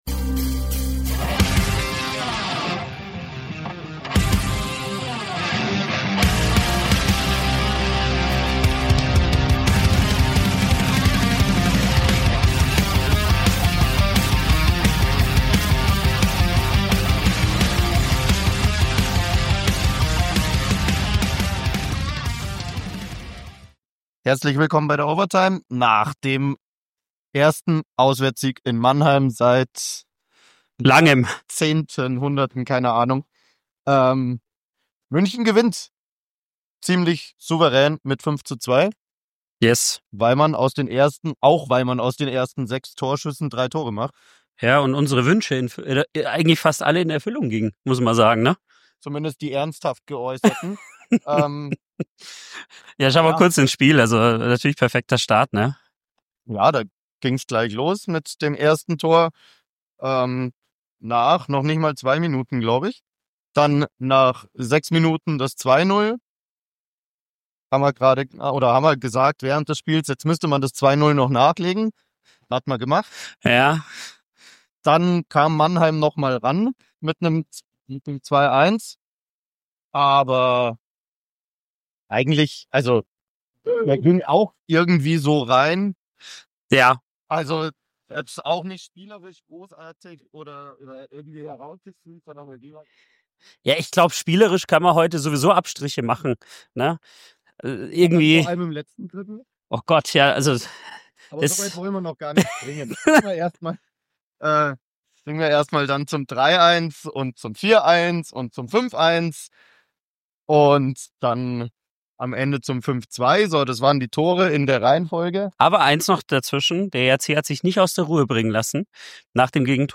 Spielzusammenfassung und Stimmen